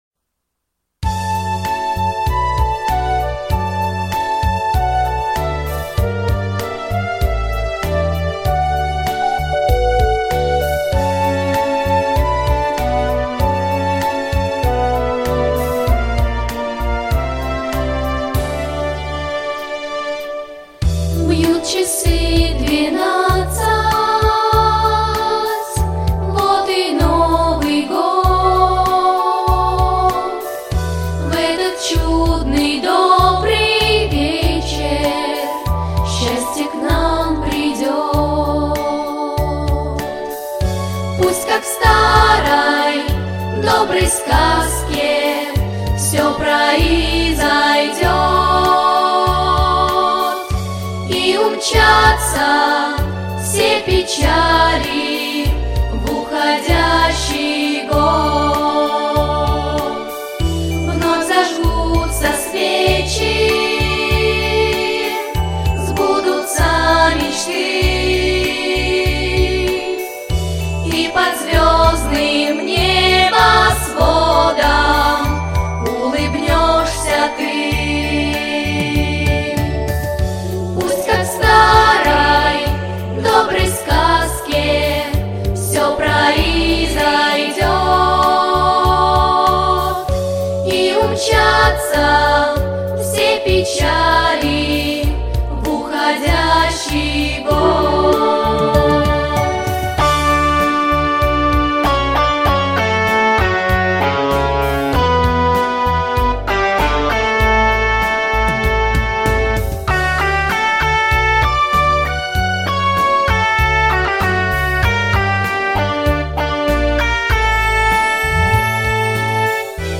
• Категория: Детские песни